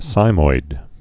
(sīmoid)